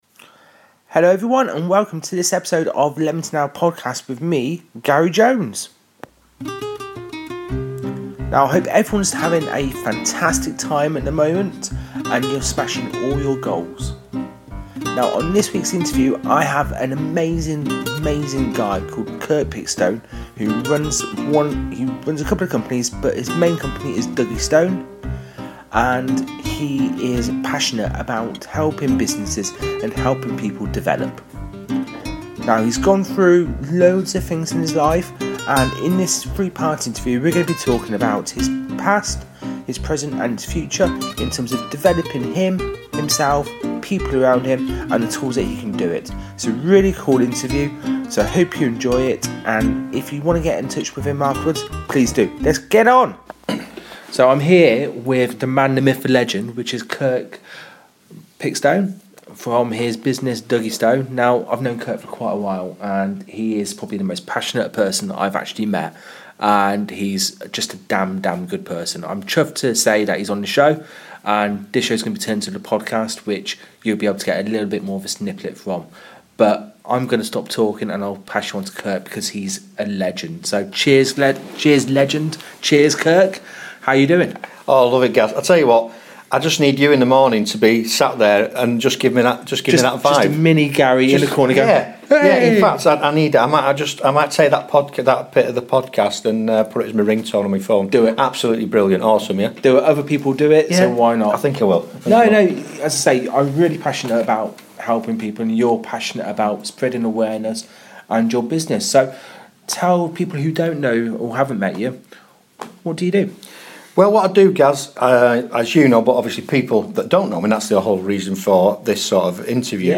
Hope you enjoy this interview as much as I did!